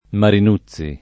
Marinuzzi [ marin 2ZZ i ] cogn.